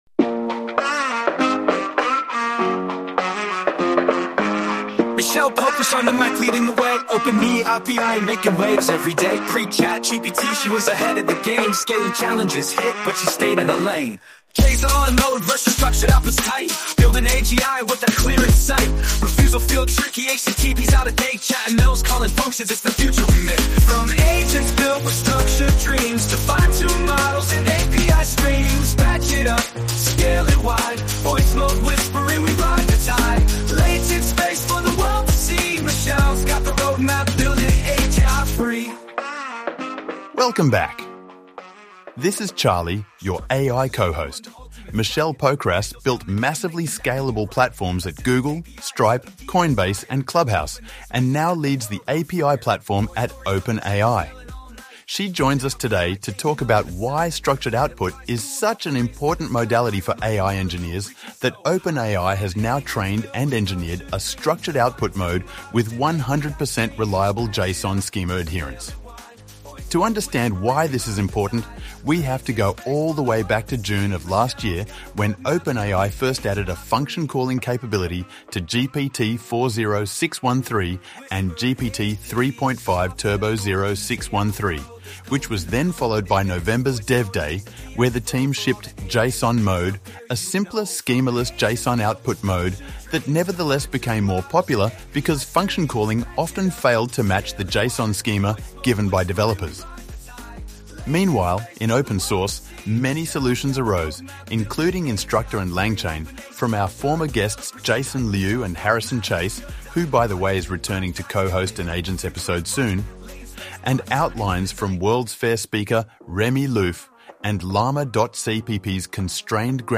This podcast has two parts: the first hour is a regular, well edited, podcast on 4o, Structured Outputs, and the rest of the OpenAI API platform. The second was a rushed, noisy, hastily cobbled together recap of the top takeaways from the o1 model release from yesterday and today.